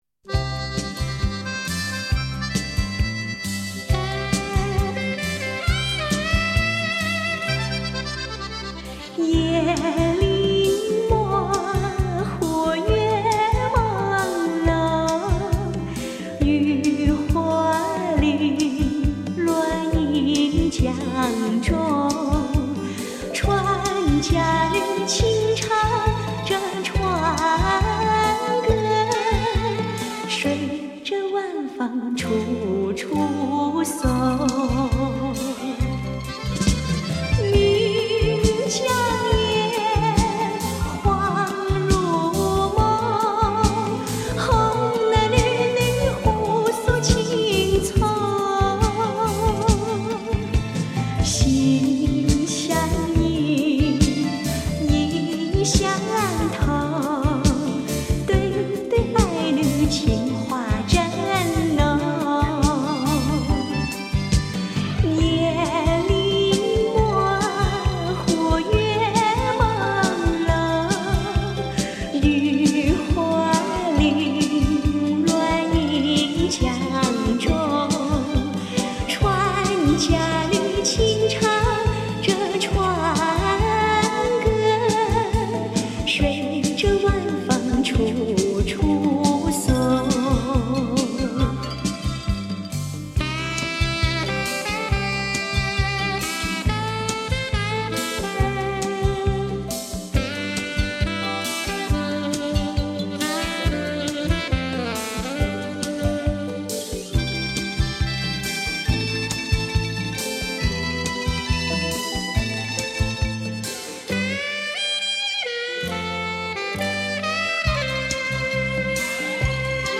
她的歌声最没有现代气息，很容易带你进入那个遥远的年代！